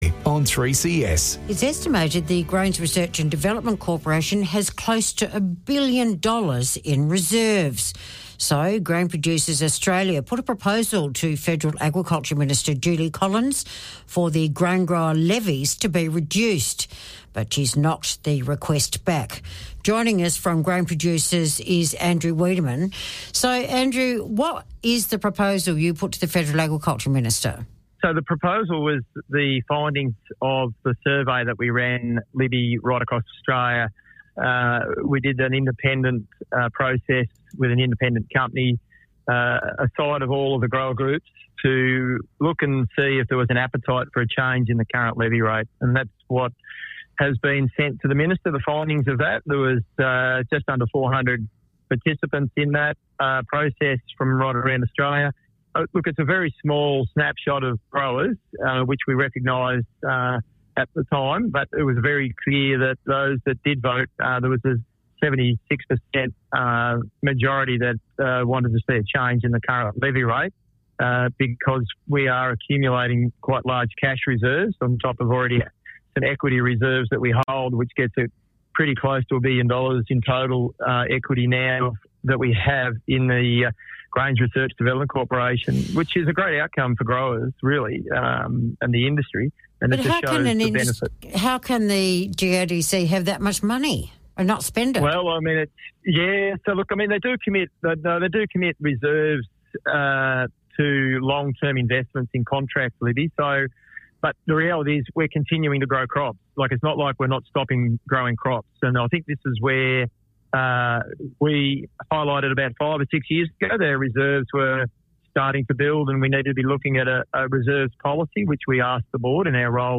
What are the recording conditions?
Speaking on 3CS radio